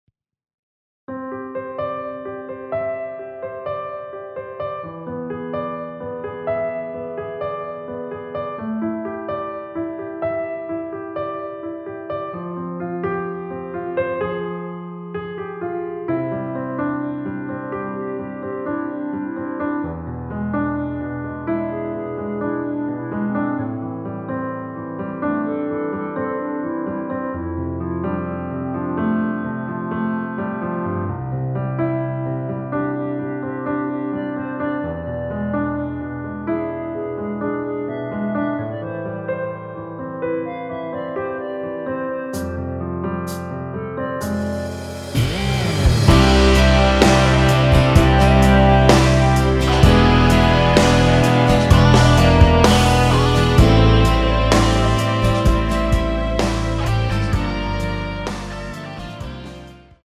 원키에서(-2)내린 (1절앞+후렴)으로 진행되게 편곡한 멜로디 포함된 MR입니다.
노래방에서 노래를 부르실때 노래 부분에 가이드 멜로디가 따라 나와서
앞부분30초, 뒷부분30초씩 편집해서 올려 드리고 있습니다.